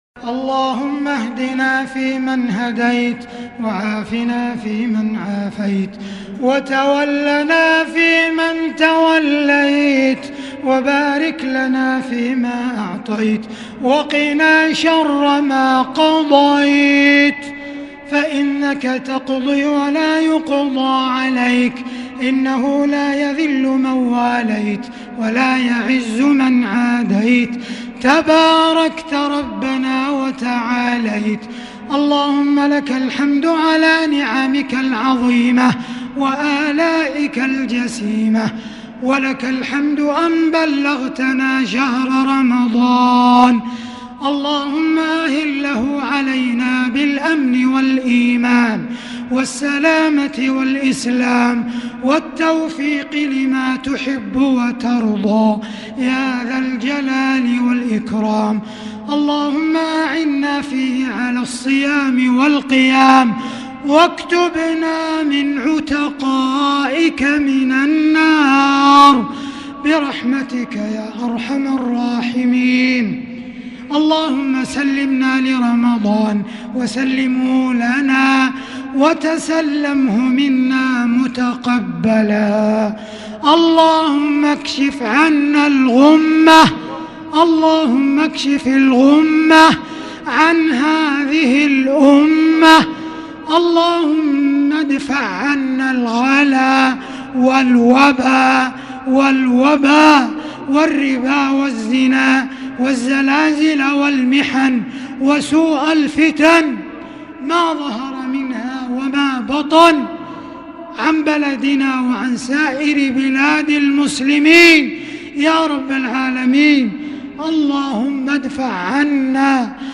دعاء القنوت ليلة 1 رمضان 1441هـ > تراويح الحرم المكي عام 1441 🕋 > التراويح - تلاوات الحرمين